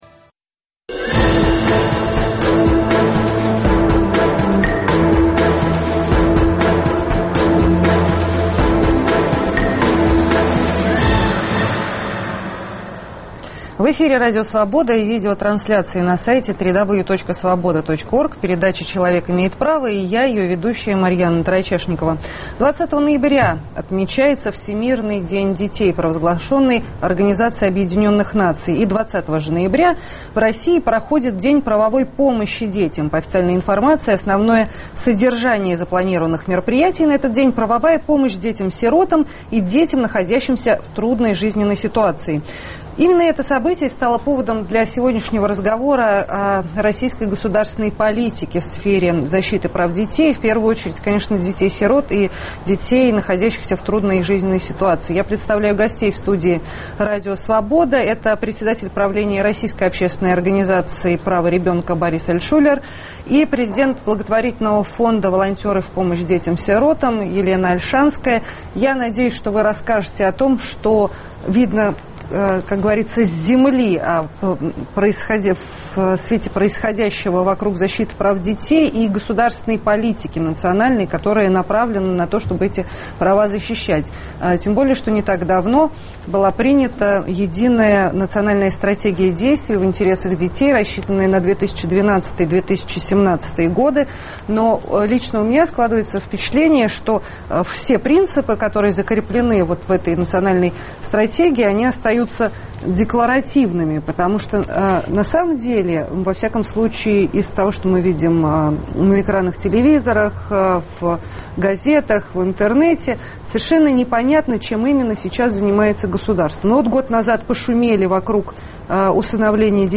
Что на самом деле может предложить государство ребенку сегодня? Почему детский омбудсмен утверждает, что западная форма ювенальной юстиции нежизнеспособна в России? В студии Радио Свобода